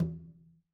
Conga-HitN_v2_rr2_Sum.wav